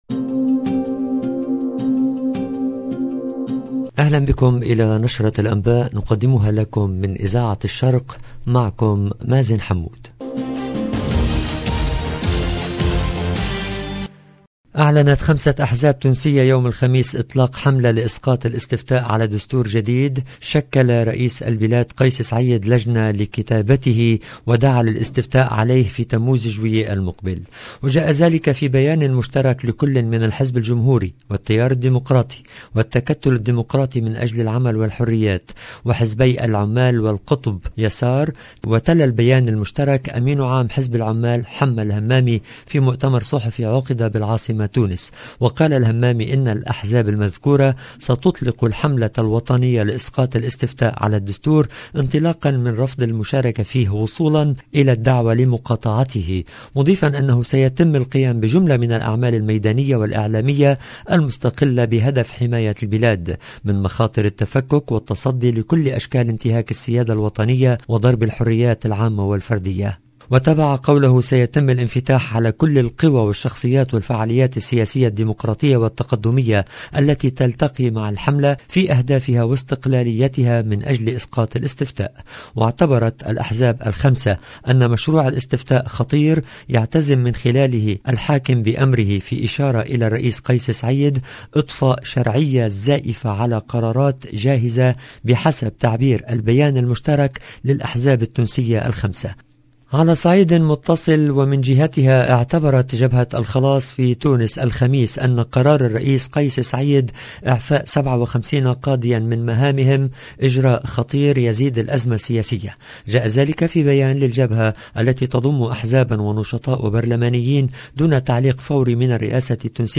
EDITION DU JOURNAL DU SOIR EN LANGUE ARABE DU 2/6/2022